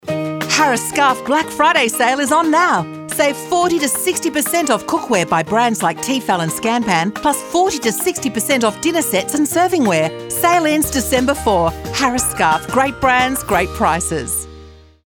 Here are some Black Friday audio ad samples that were heard across the world this year: